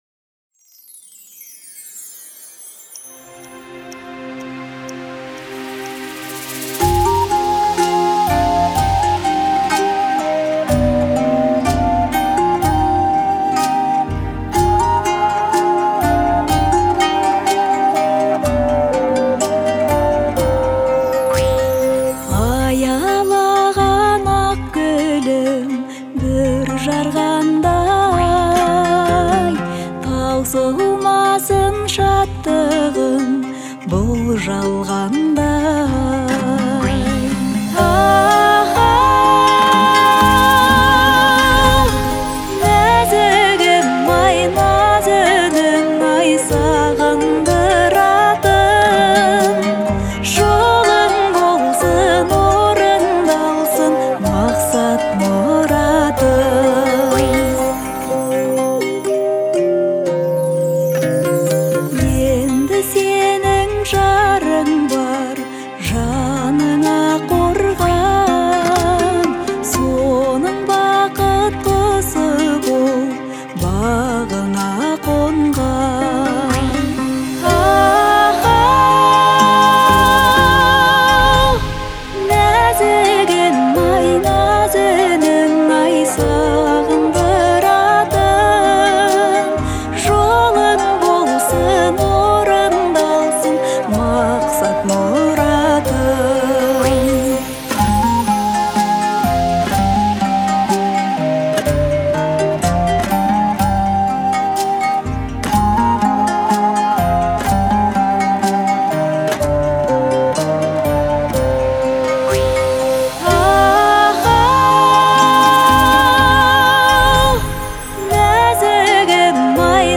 выделяется своим мягким вокалом и искренностью исполнения